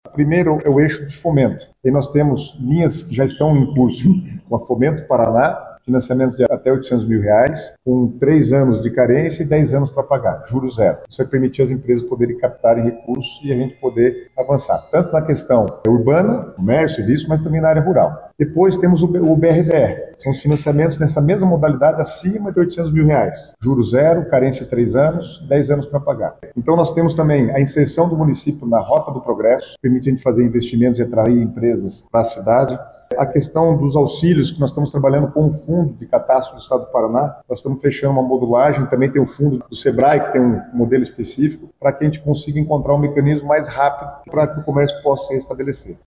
O secretário das Cidades, Guto Silva, falou sobre o plano que ainda está em fase de elaboração e detalhou a parte de fomento.